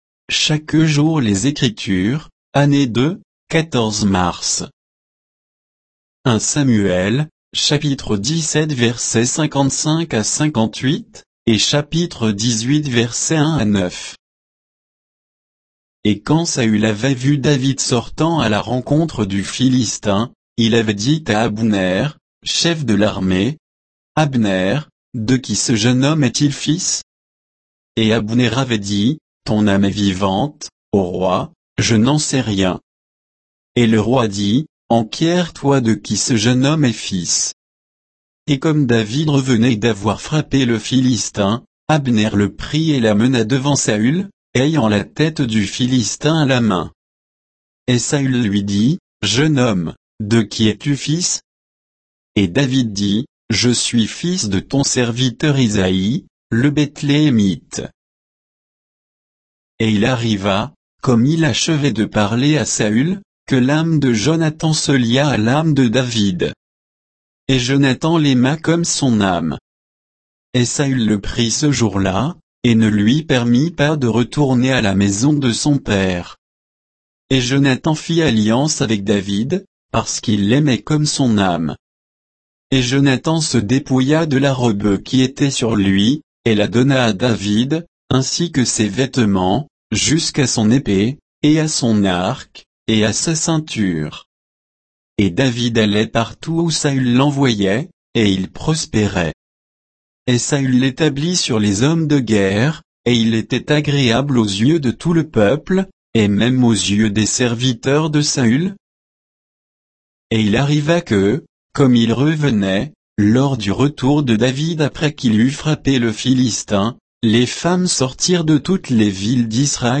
Méditation quoditienne de Chaque jour les Écritures sur 1 Samuel 17